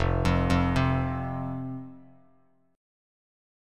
F5 Chord
Listen to F5 strummed